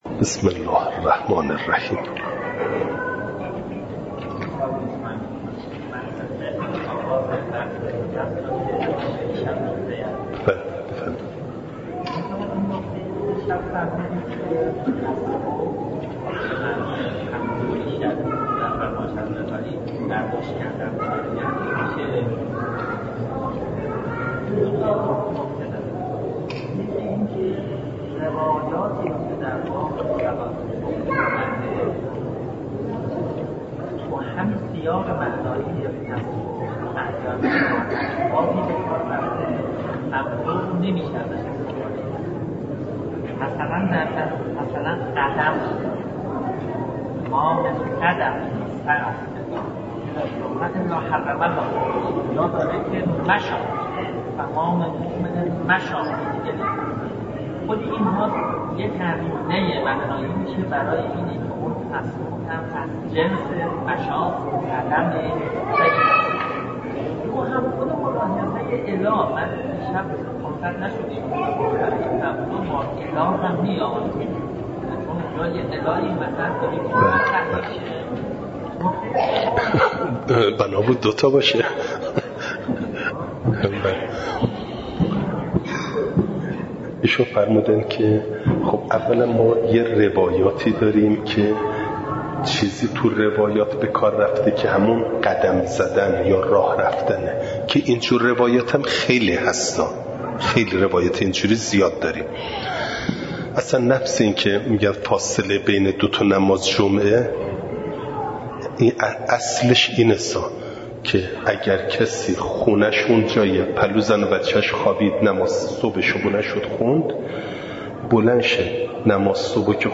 خارج فقه – نماز جمعه (جلسه ۲) – دروس استاد